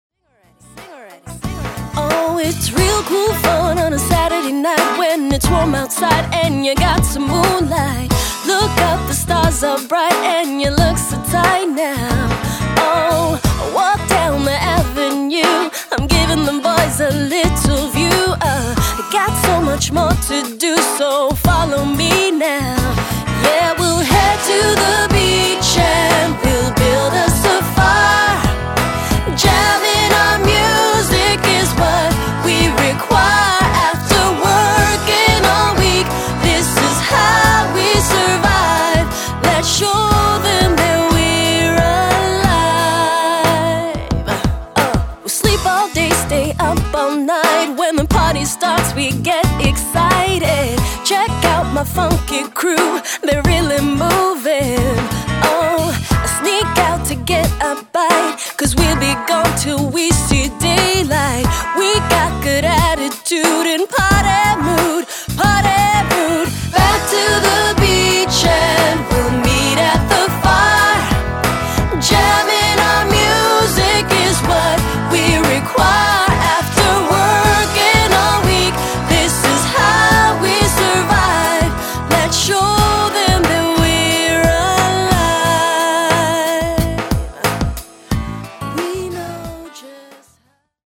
get-ya-groovin’
summer tunes